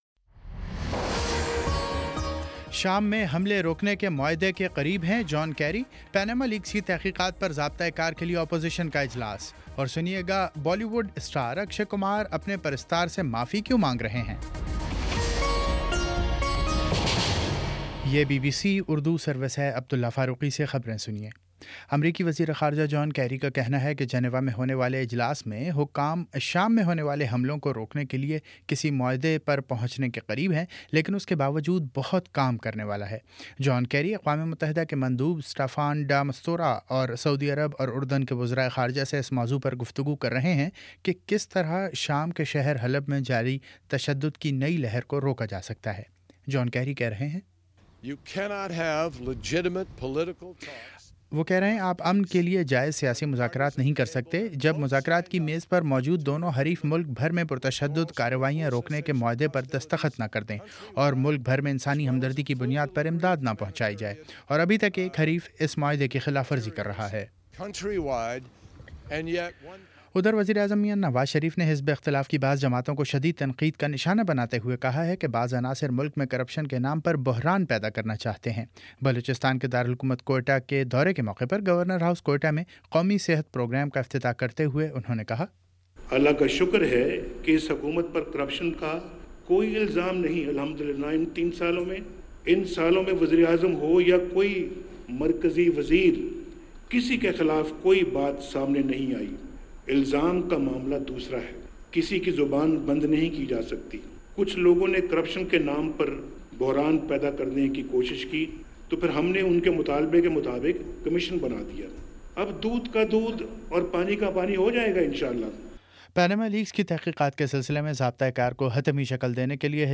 مئی 02 : شام چھ بجے کا نیوز بُلیٹن